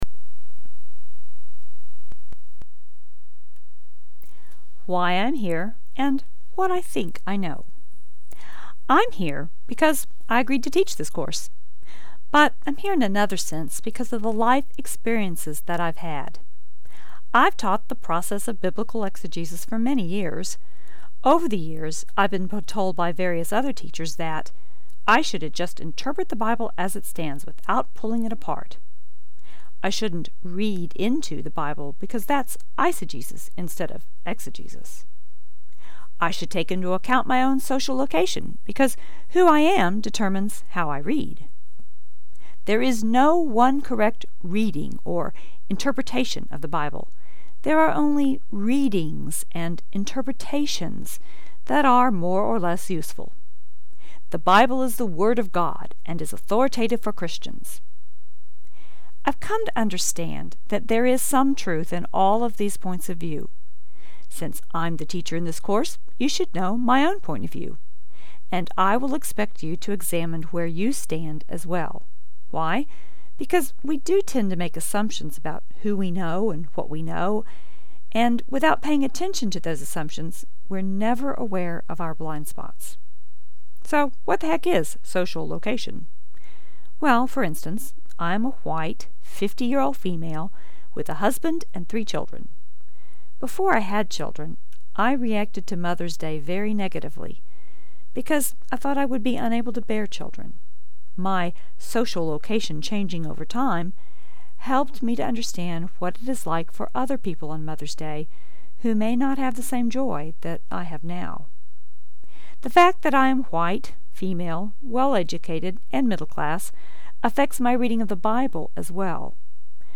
Audio of this post (Note that this is from my online class and is slightly different from the written post):